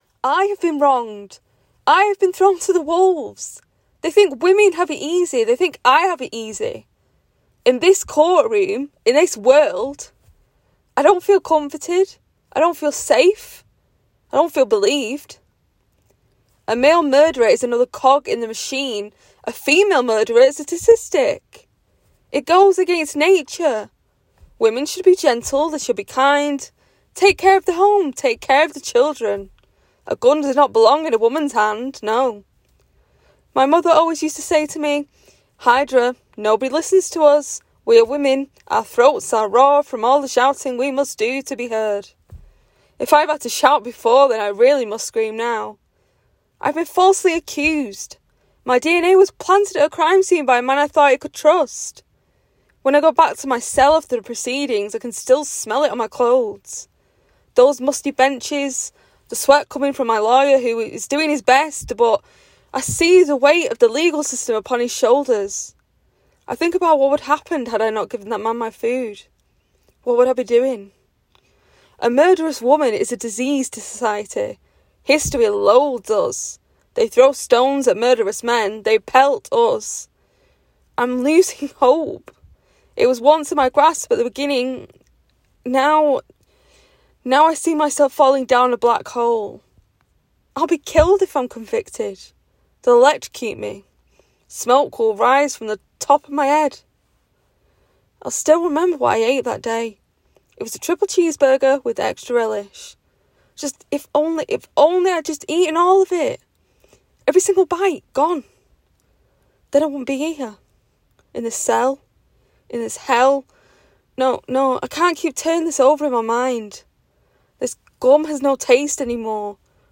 Author’s Note: This monologue is from a play I am in the process of writing. I have also acted it out in the audio below. Bear in mind that I haven’t done any acting for ten years…